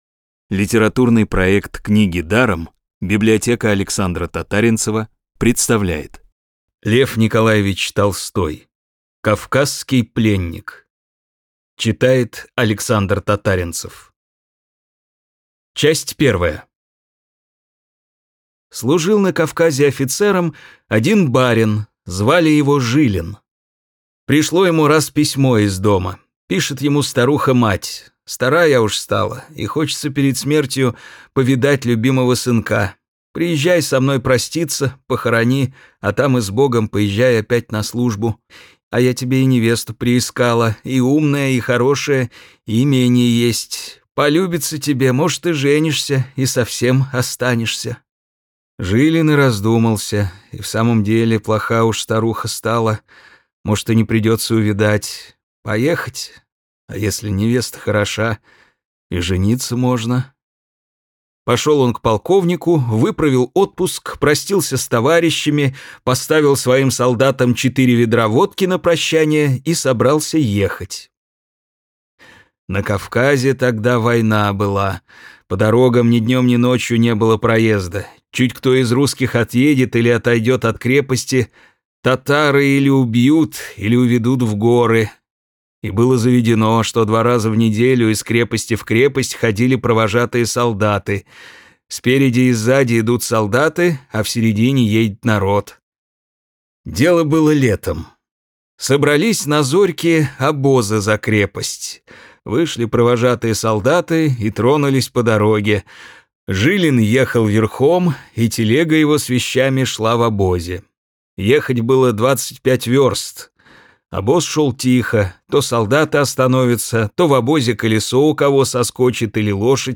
Бесплатная аудиокнига «Кавказский пленник» от Рексквер.